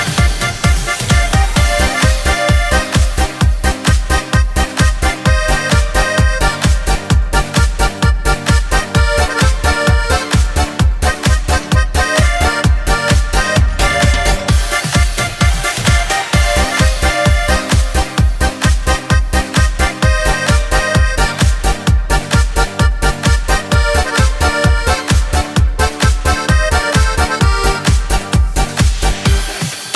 Баян микс